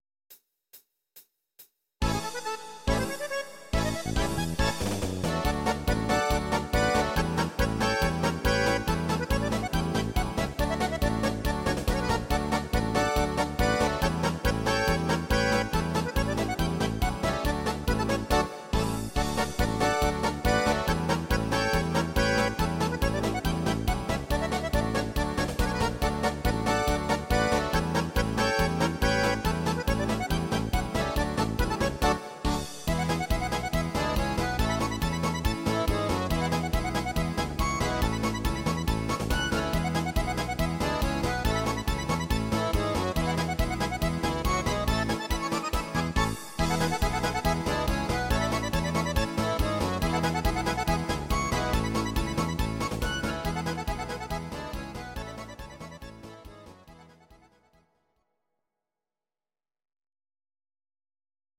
These are MP3 versions of our MIDI file catalogue.
Please note: no vocals and no karaoke included.
Akkordeon